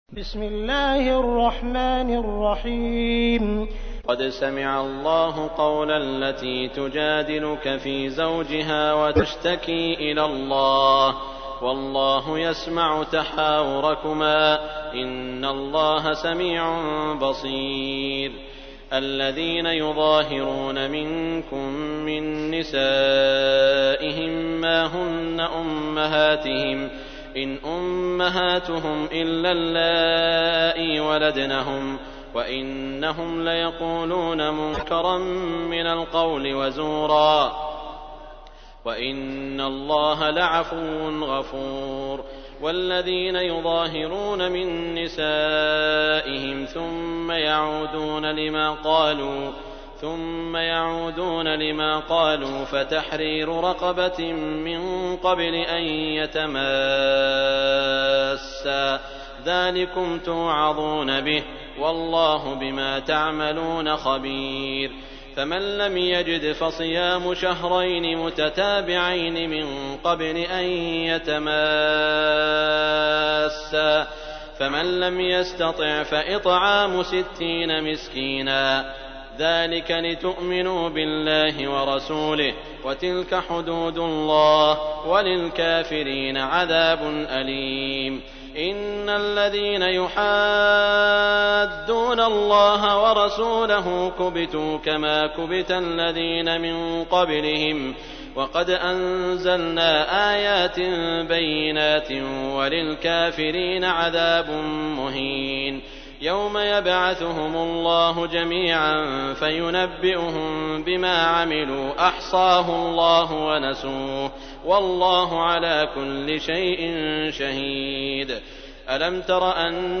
تحميل : 58. سورة المجادلة / القارئ عبد الرحمن السديس / القرآن الكريم / موقع يا حسين